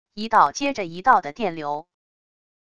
一道接着一道的电流wav音频